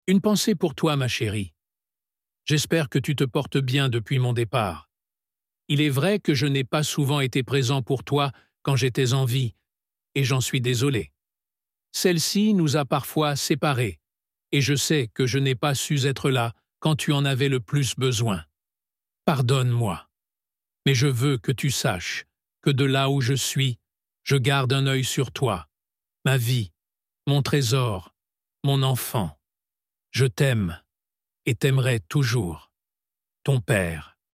VOCAL, la voix des défunts
Cette voix a été clonée par l'intelligence artificielle